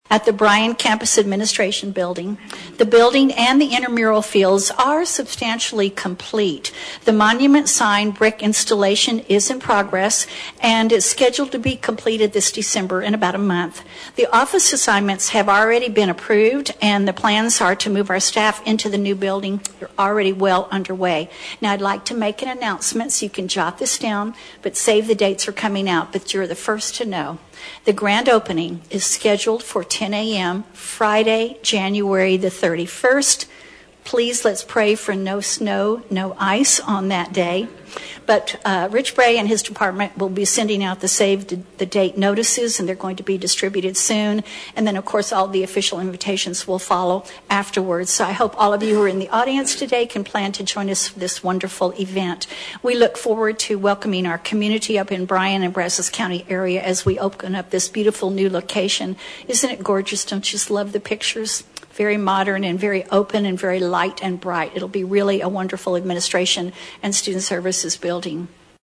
during the November 19, 2024 Blinn College trustees meeting.